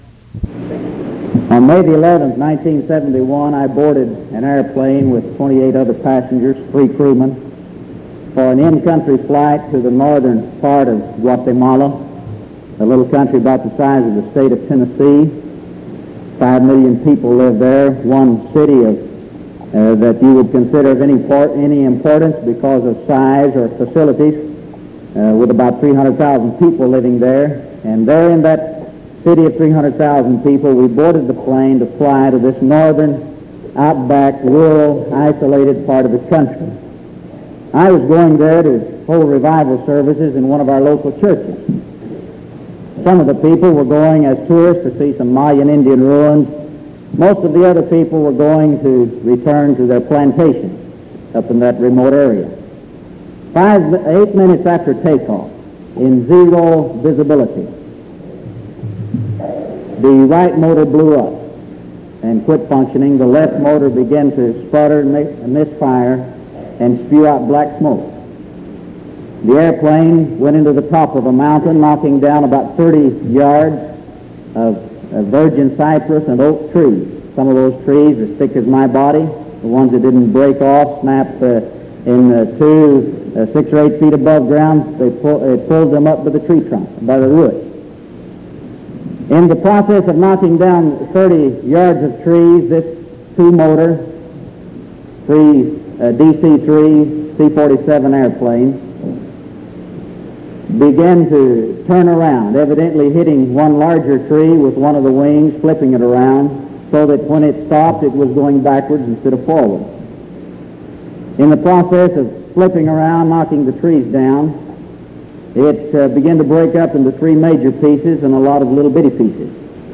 Sermon December 2nd 1973 PM